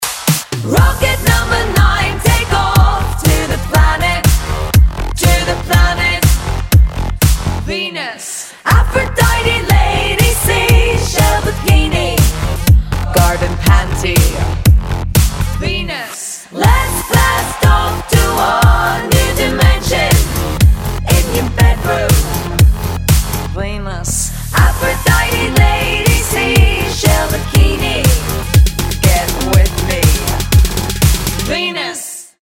• Качество: 192, Stereo
ритмичные
женский вокал
мелодичные
зажигательные
Electropop